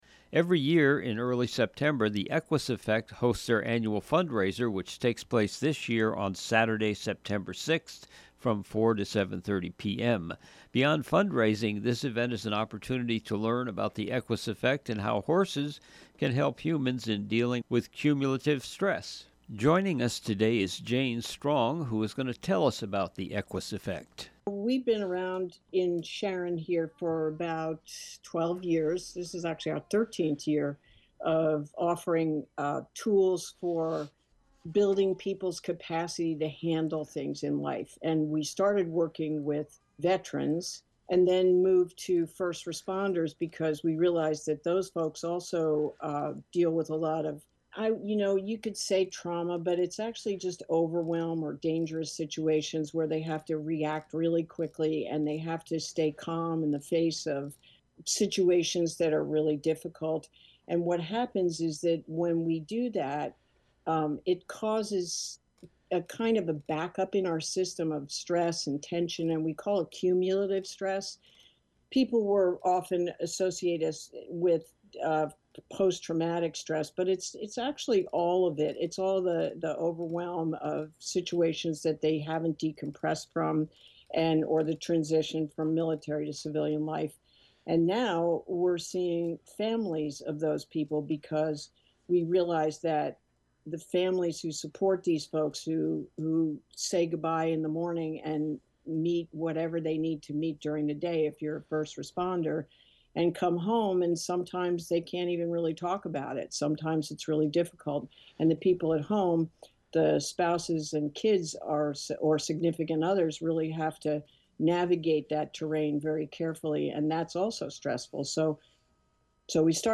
ROBIN HOOD RADIO INTERVIEWS Interview